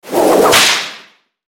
Звуки дикого запада
Звук, когда ковбой щелкнул хлыстом